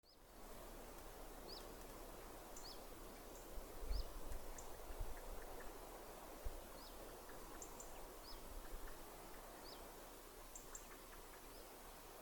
Čuņčiņš, Phylloscopus collybita
StatussDzirdēta balss, saucieni
Piezīmes/dzirdēts sauciens, putns nav novērots.